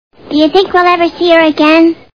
The Simpsons [Lisa] Cartoon TV Show Sound Bites